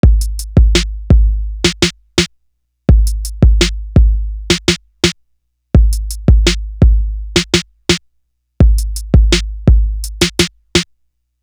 Essence Drum.wav